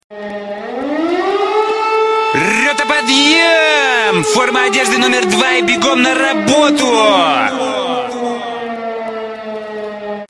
На этой странице собраны звуки армейского подъема и ротных построений — от сигналов горна до команд дежурного.
Ещё один будильник